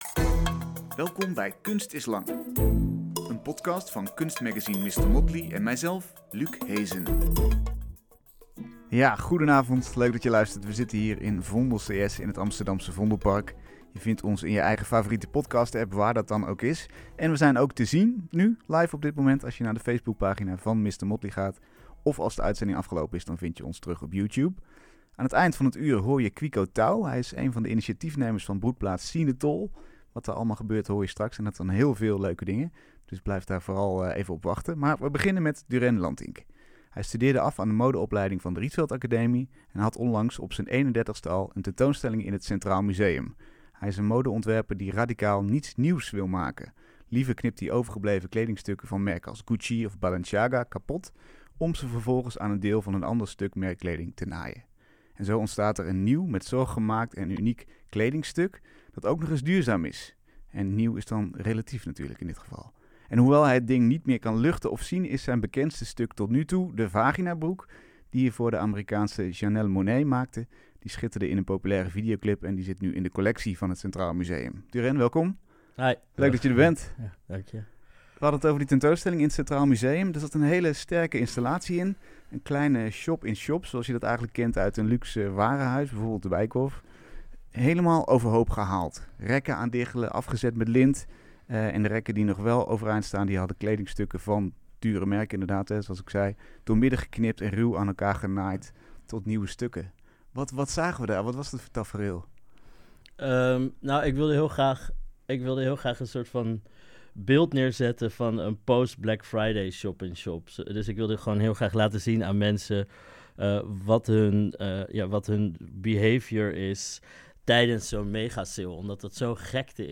Hij knipt afgedankte haute couture van de grote modemerken aan stukken, en maakt er unieke en waardevolle kledingstukken van. Een gesprek over je individualiteit vormgeven en de toekomst van mode.